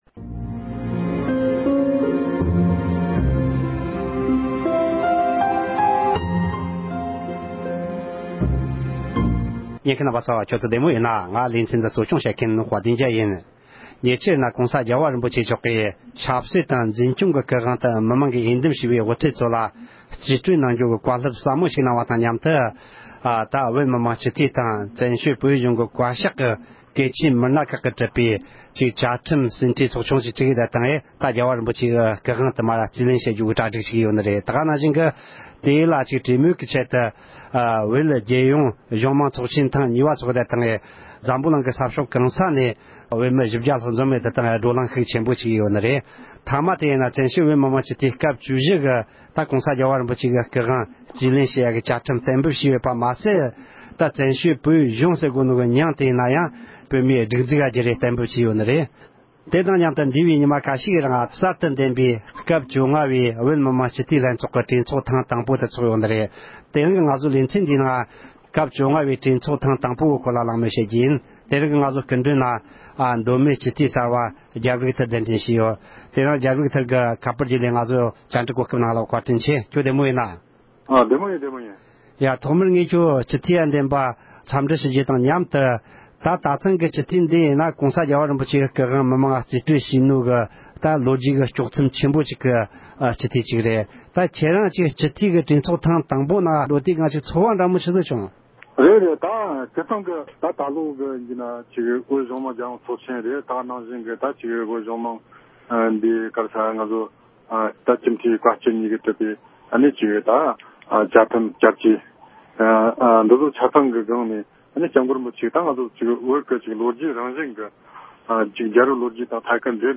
སྐབས་བཅོ་ལྔ་བའི་བོད་མི་མང་སྤྱི་འཐུས་ལྷན་ཚོགས་ཀྱི་གྲོས་ཚོགས་དང་པོའི་ཐད་གླེང་མོལ།